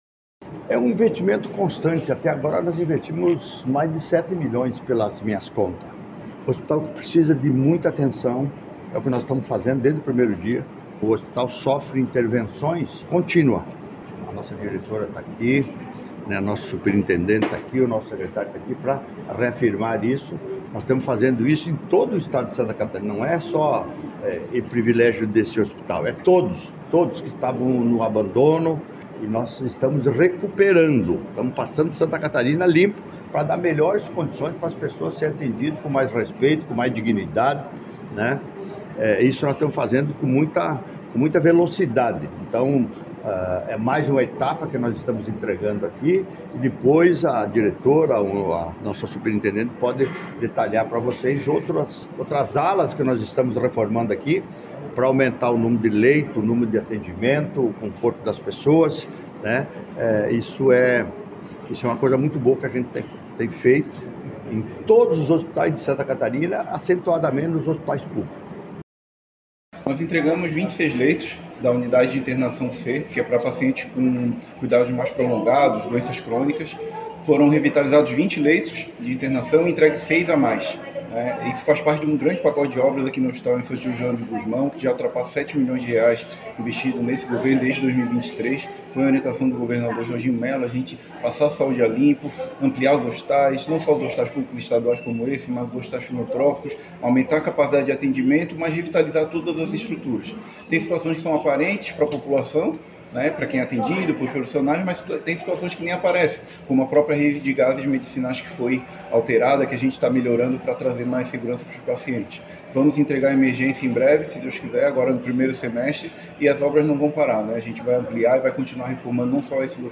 Segundo o governador, este é um hospital que precisa de muita atenção e é o governo está fazendo desde o primeiro dia:
O secretário de Estado da Saúde, Diogo Demarchi destacou que a entrega faz parte de um grande pacote de obras no Hospital Infantil Joana de Gusmão que já ultrapassa R$ 7 milhões investidos: